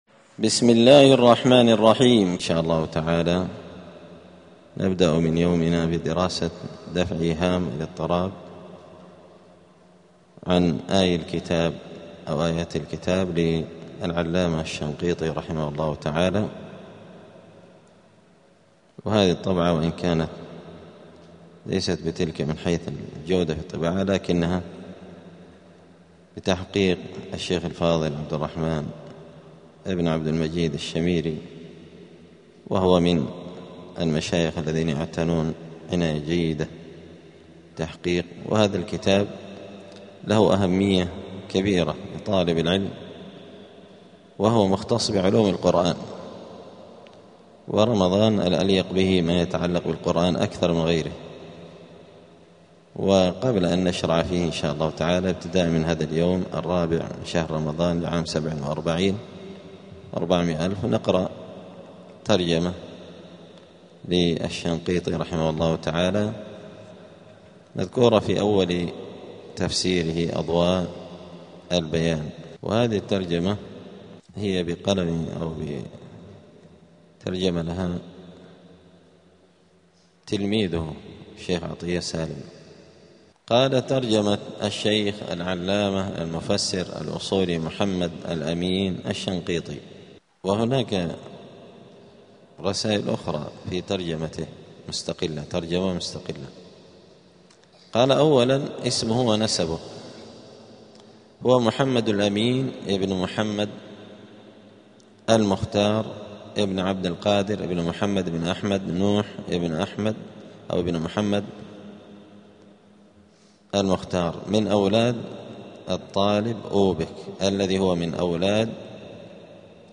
الأثنين 6 رمضان 1447 هــــ | الدروس، دروس القران وعلومة، دفع إيهام الاضطراب عن آيات الكتاب | شارك بتعليقك | 7 المشاهدات